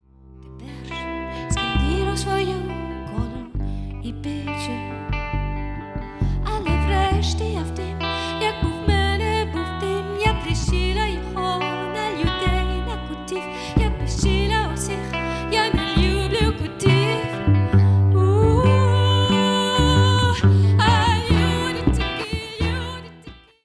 Gesang, Performance
Gitarre, Sounds
Kontrabass
Live-Mitschnitte unseres ersten Konzerts: